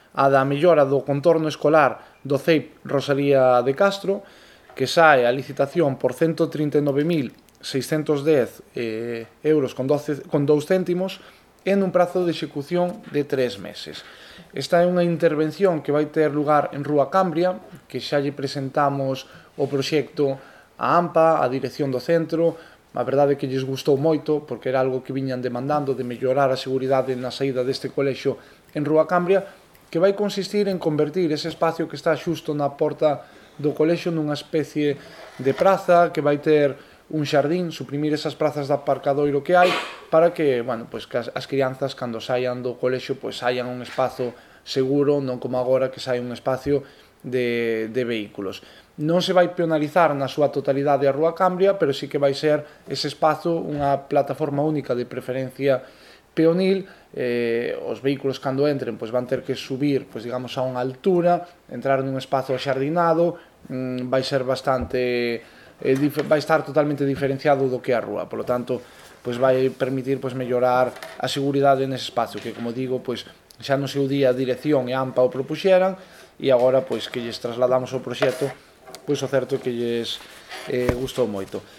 Rolda de prensa Xunta de Goberno Local